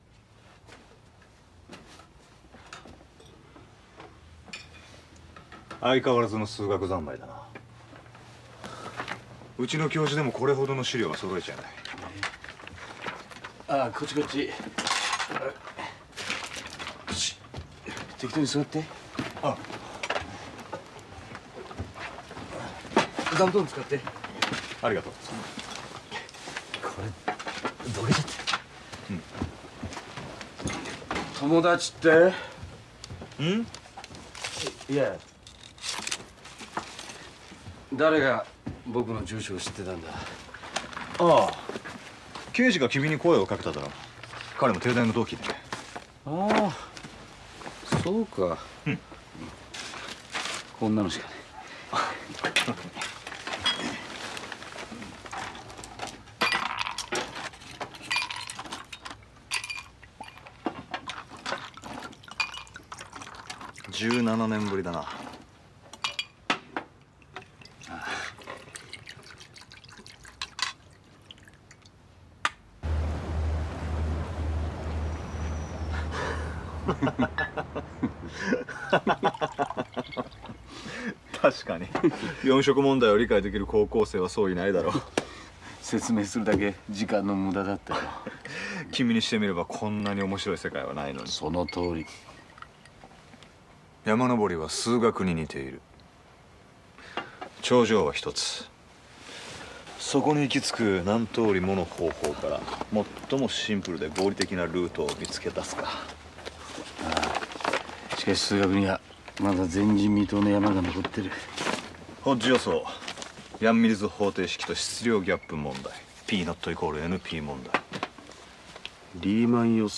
IMDb link The fourth scene a discussion about all kind of math, four color theorem, the Millenium problems.